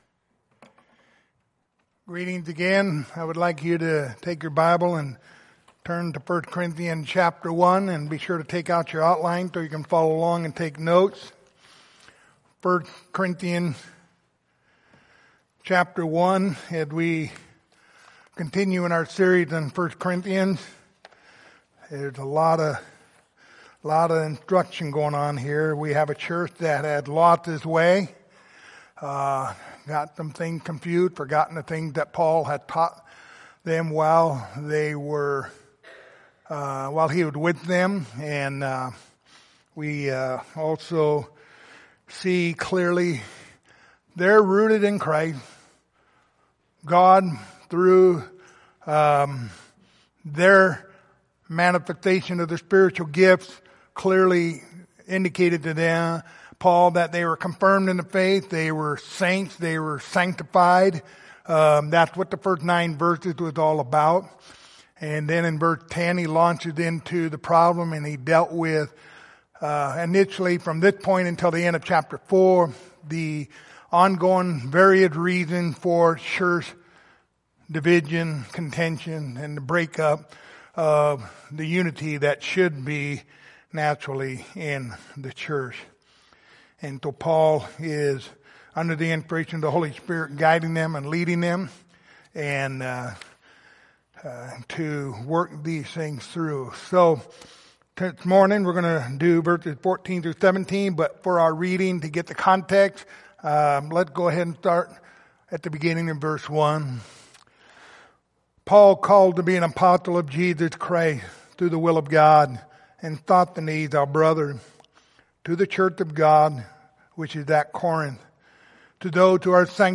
1 Corinthians 1:14-17 Service Type: Sunday Morning Topics